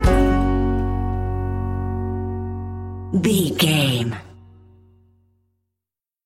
Ionian/Major
drums
acoustic guitar
violin
Pop Country
country rock
bluegrass
uplifting
driving
high energy